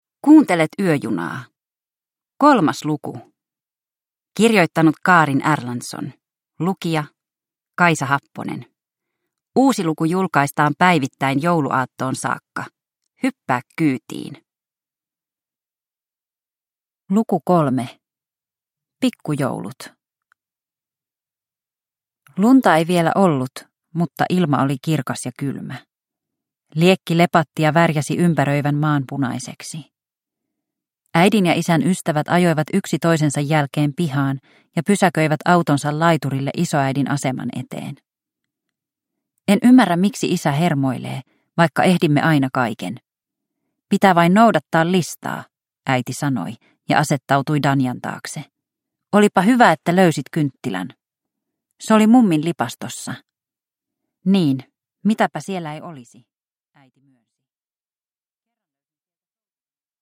Yöjuna luku 3 – Ljudbok